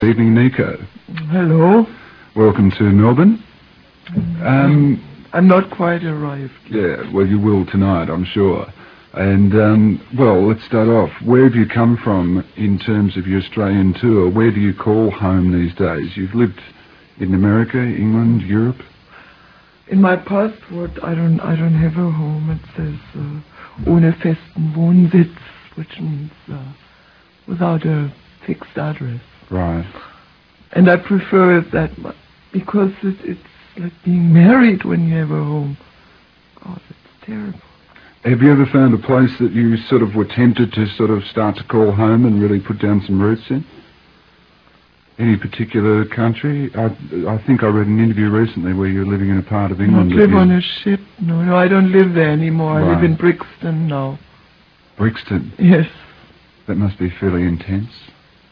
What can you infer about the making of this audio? Australian Radio (Real Audio 3.0 sample, 1min)